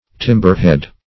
Search Result for " timberhead" : The Collaborative International Dictionary of English v.0.48: Timberhead \Tim"ber*head`\, n. (Naut.) The top end of a timber, rising above the gunwale, and serving for belaying ropes, etc.; -- called also kevel head .